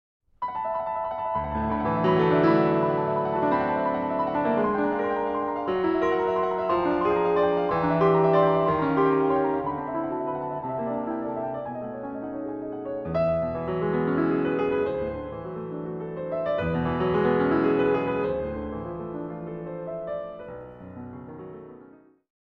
Works for piano